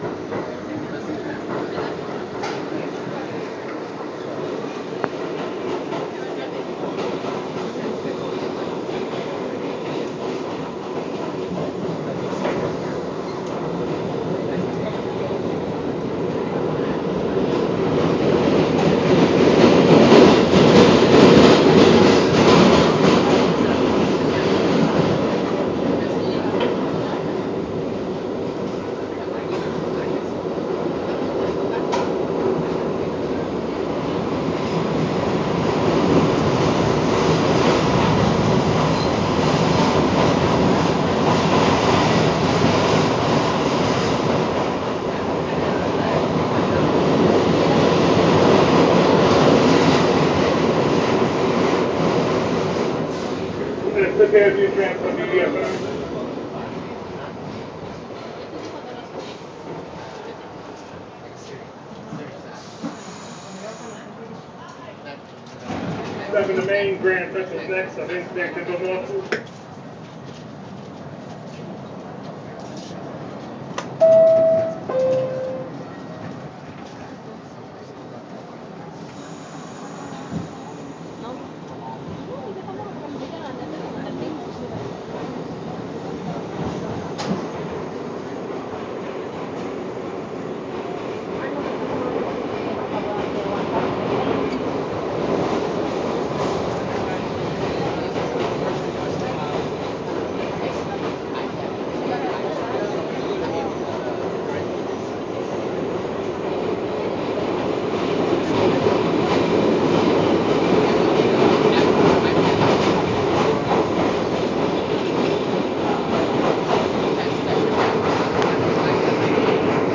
NYC Subway
Announcer ding dong Field-recording Metro NYC PA Subway sound effect free sound royalty free Sound Effects